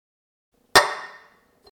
Звуки открытия пива
Банку пива поставили на стол